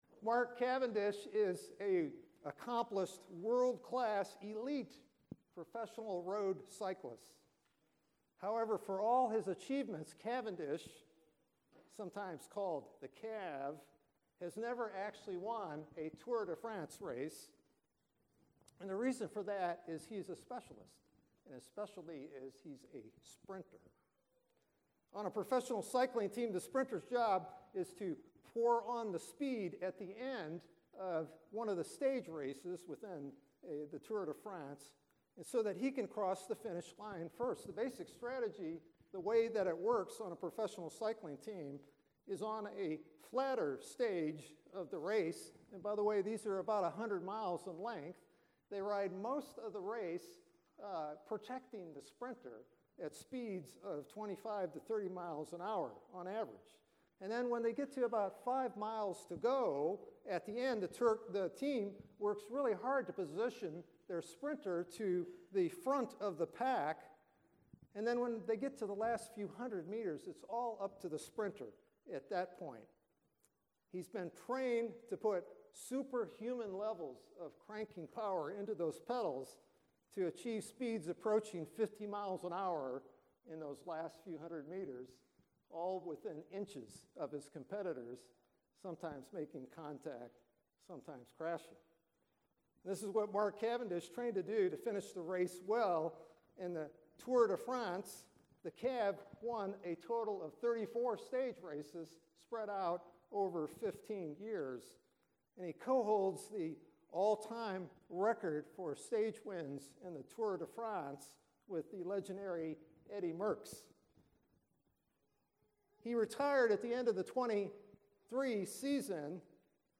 Sermons | Trinity Presbyterian Church